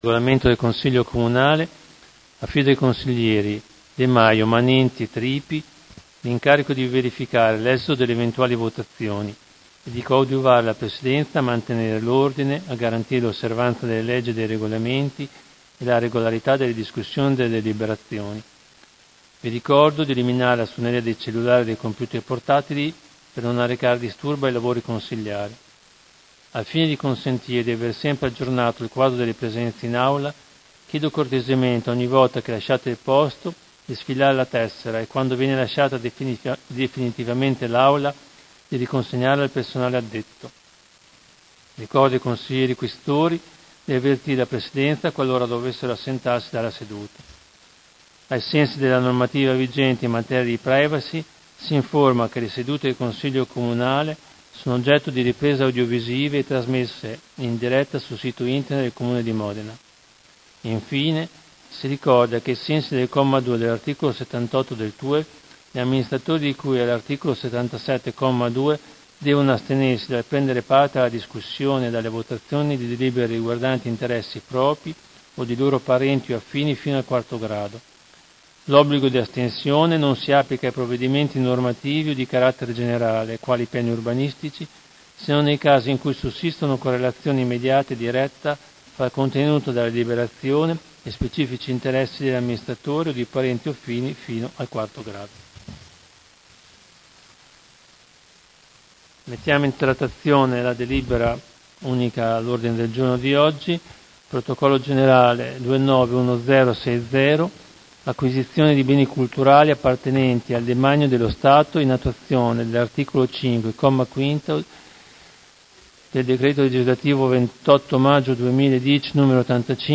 Seduta del 17/10/2019 Apre ai lavori del Consiglio Comunale.